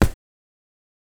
hit.wav